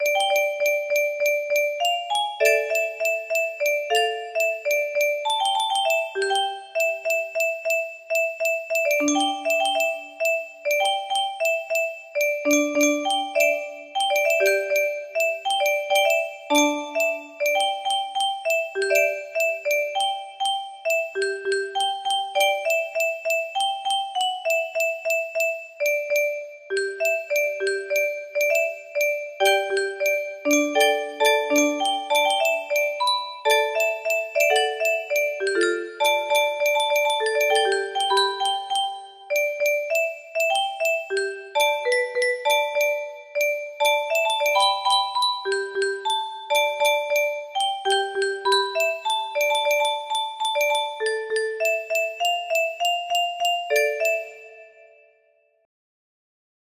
Unknown Artist - Untitled music box melody
Imported from MIDI from imported midi file (24).mid